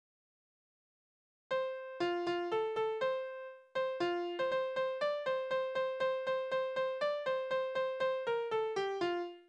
Ringlieder: Was tust du damit?
Tonart: F-Dur
Taktart: 4/4
Tonumfang: große Sexte
Besetzung: vokal